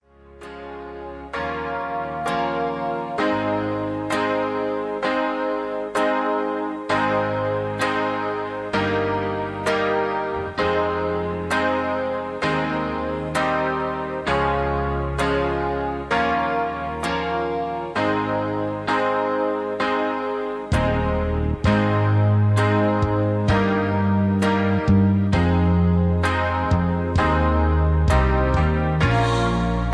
Key-Ab) Karaoke MP3 Backing Tracks
Just Plain & Simply "GREAT MUSIC" (No Lyrics).
Tags: karaoke , mp3 backing tracks